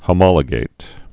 (hə-mŏlə-gāt, hō-)